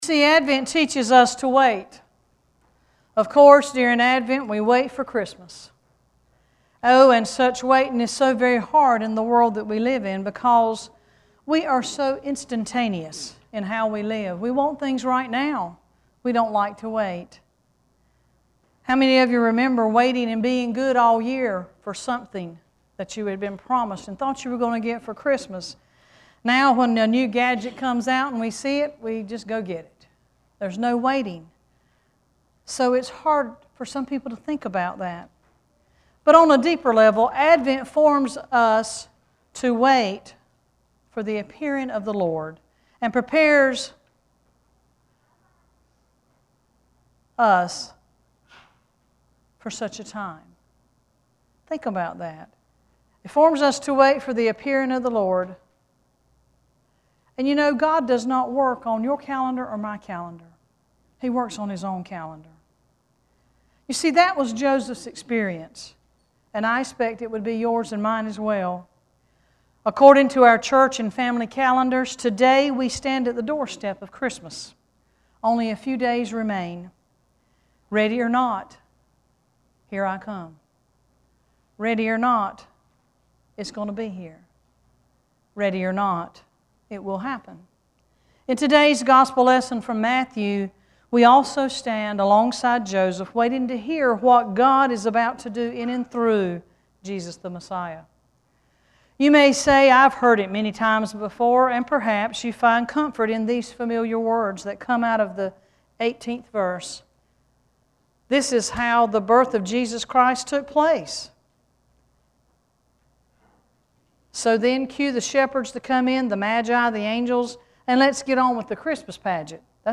Worship Service 12-22-13: In This Strange and Surprising Way